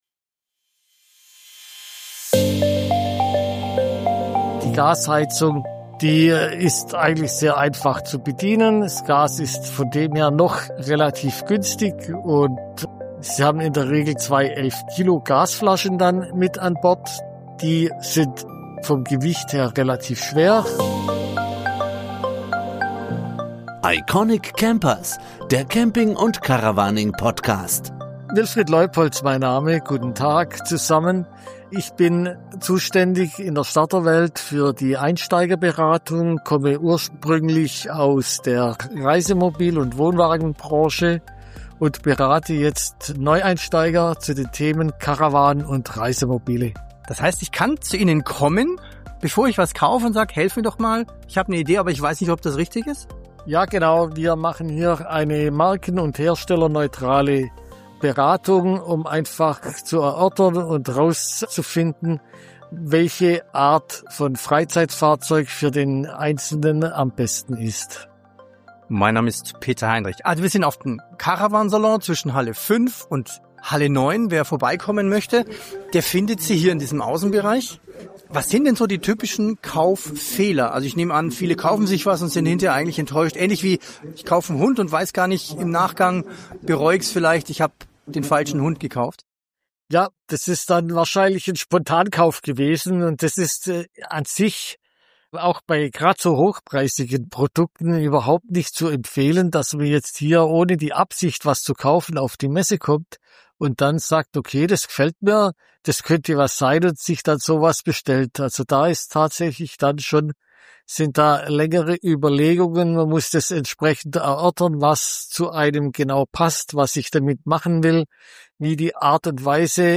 Auf dem Caravan Salon 2025 zwischen Halle 5 und 7 gibt es kostenfreie Beratung für alle, die über den Kauf eines Caravans, Zelts oder Wohnmobils nachdenken.